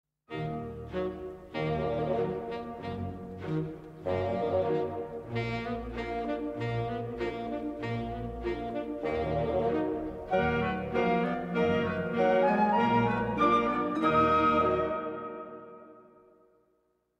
Звук заставки